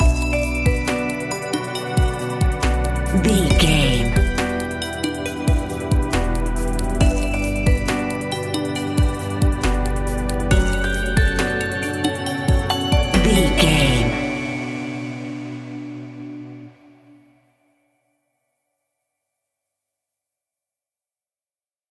Aeolian/Minor
groovy
dreamy
peaceful
smooth
drum machine
synthesiser
house
instrumentals